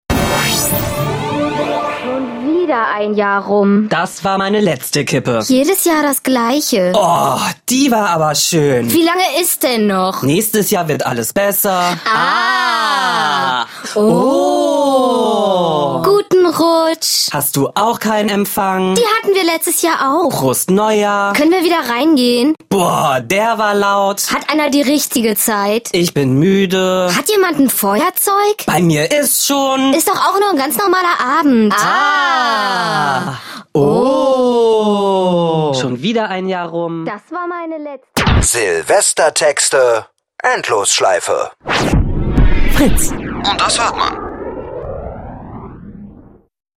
Endlosschleife "Silvestertexte" | Fritz Sound Meme Jingle